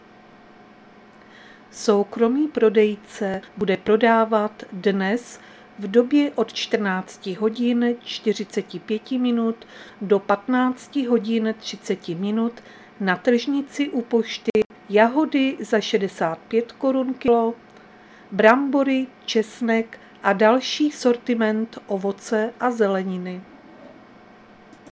Záznam hlášení místního rozhlasu 23.4.2025
Zařazení: Rozhlas